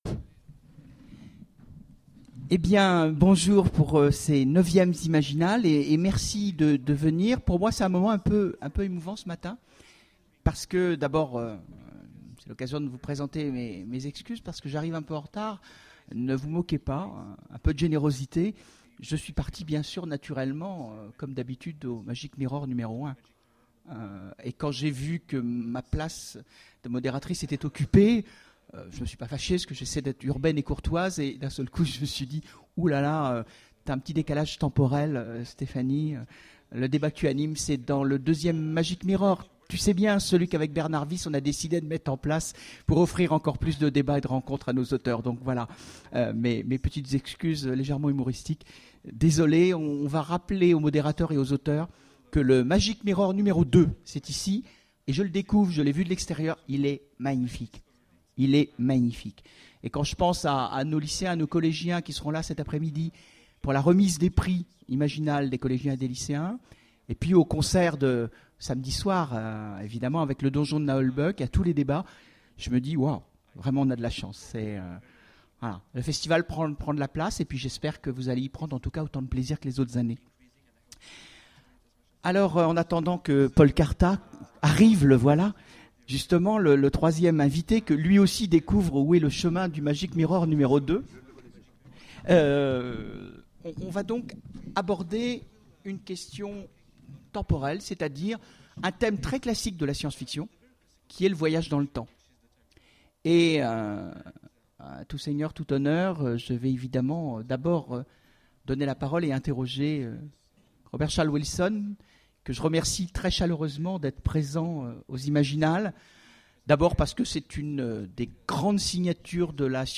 Voici l'enregistrement de la conférence Voyageurs temporels... Du rêve au cauchemar ! aux Imaginales 2010